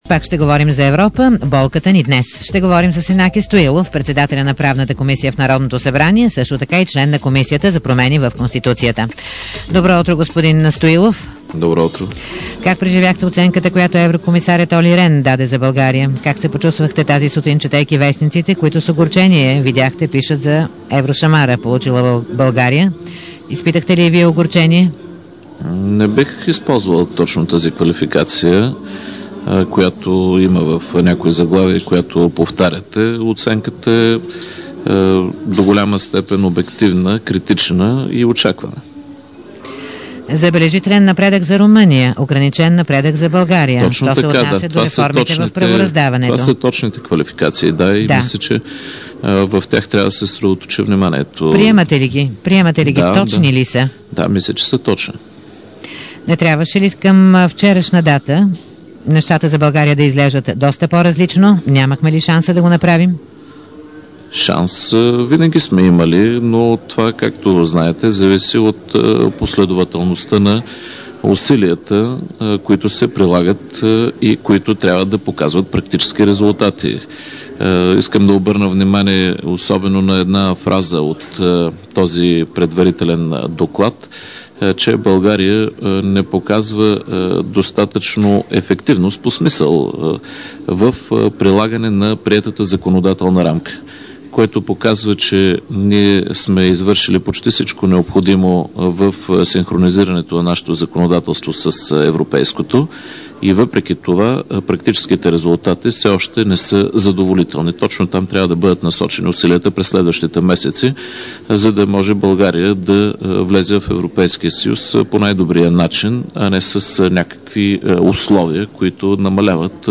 Янаки Стоилов, председател на правната комисия в парламента, в интервю